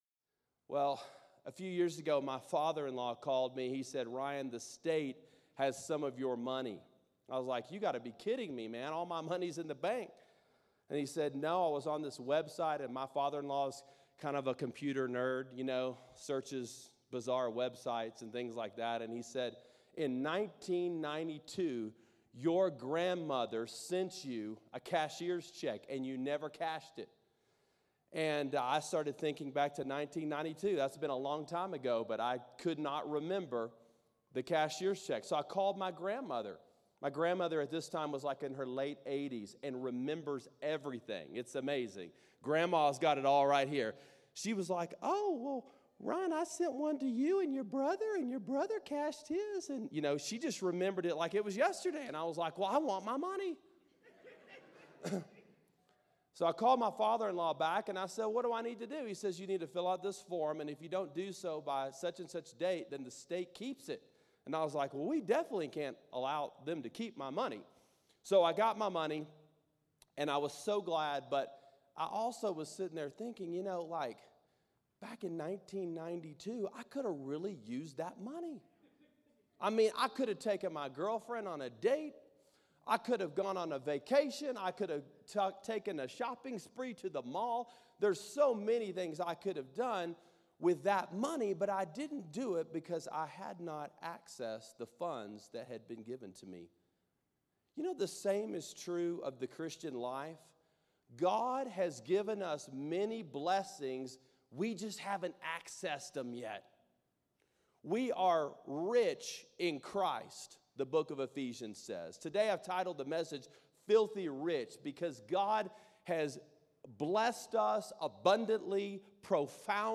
YOLO: Filthy Rich: Ephesians 1:1-11 – Sermon Sidekick